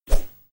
sfx_woosh_0.mp3